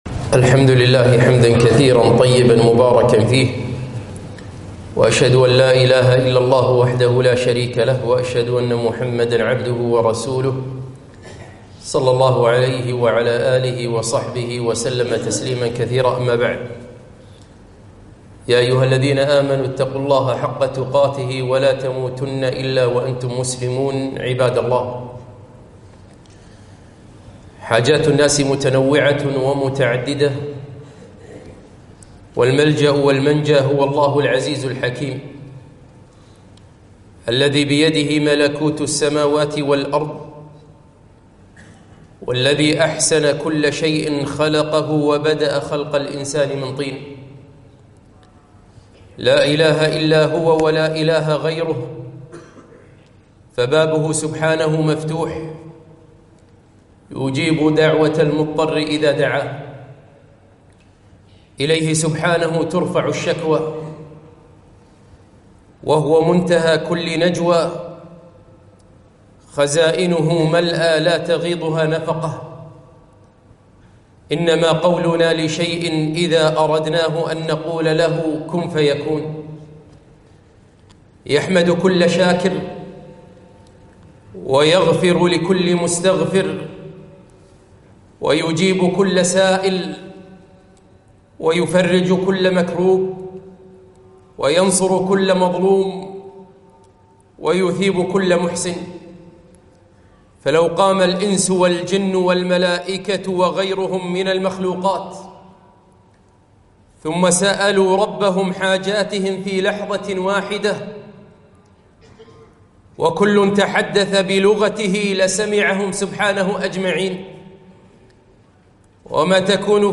خطبة - تضرعوا إلى الله بالدعاء